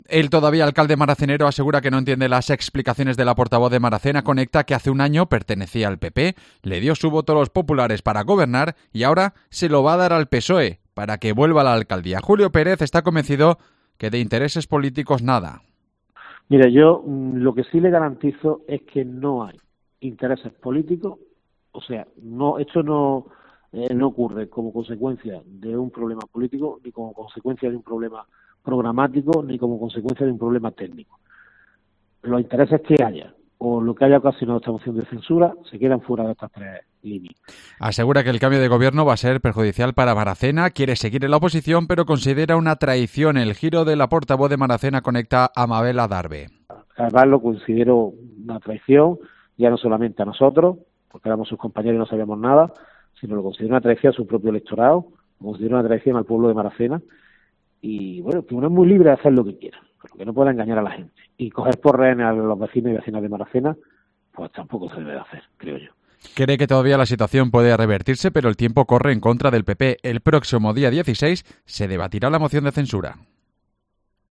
Julio Pérez, alcalde de Maracena